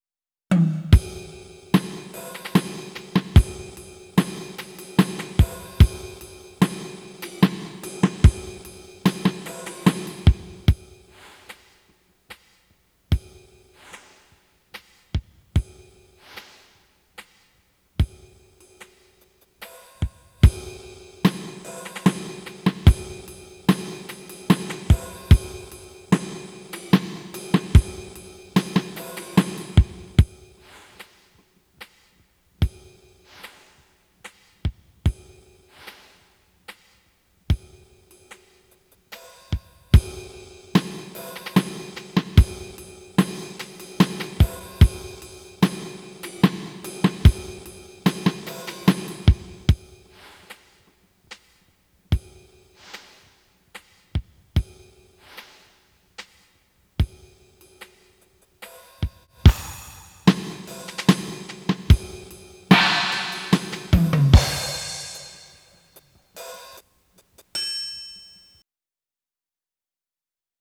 Dynamics- piano and forte
Click on the examples to hear samples of beat tracks customized for Stroking class.
Piano & Forte
SlowWaltzPianoForte.wav